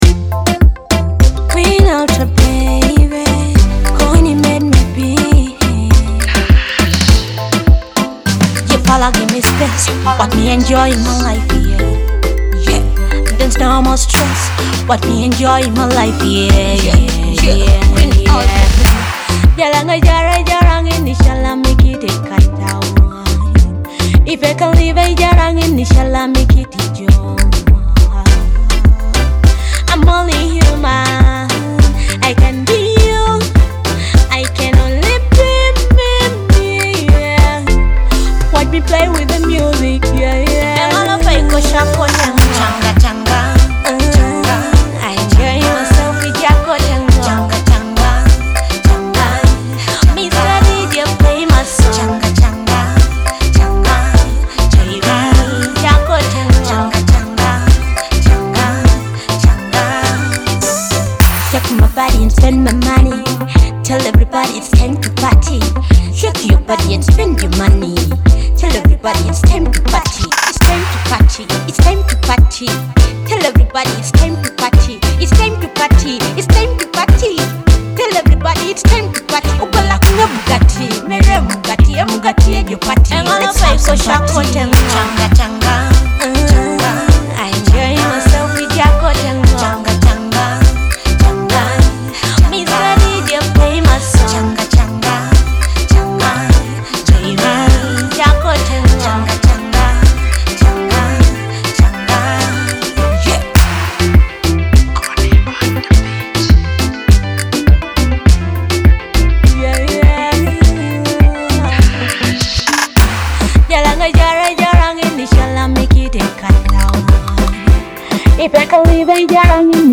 Teso music